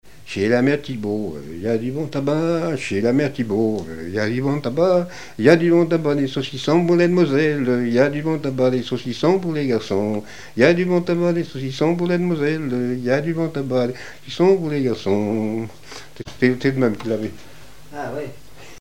Chants brefs - A danser
danse : scottich trois pas
Activité du violoneux
Pièce musicale inédite